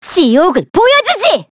Yanne_L-Vox_Skill3_kr.wav